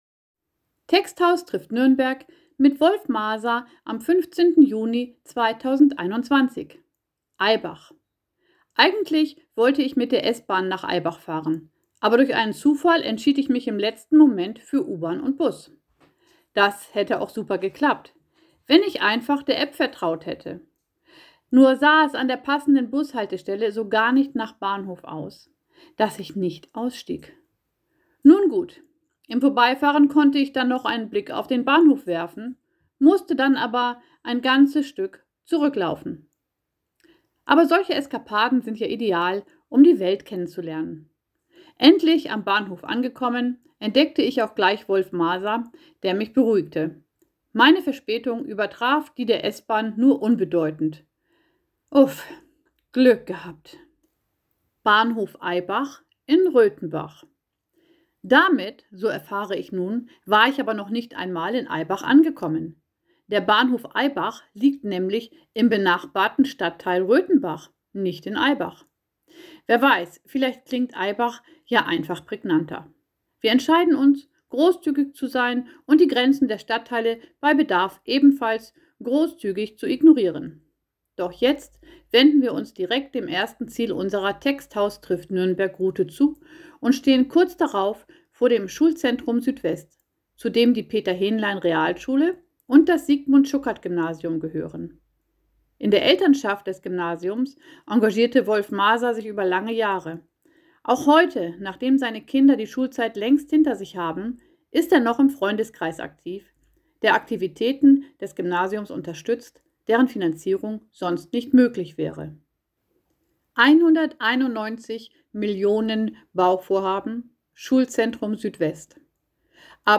Die Reportagen gibt es jetzt auch als Audio.